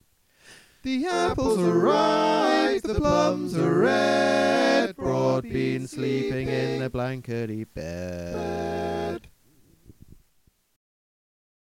Key written in: F Major
How many parts: 4
Type: Barbershop
All Parts mix: